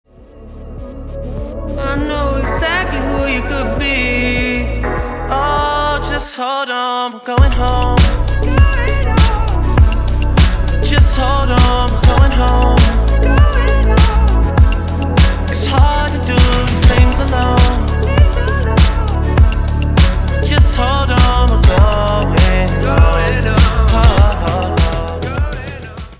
красивый мужской голос
Synth Pop
dance
RnB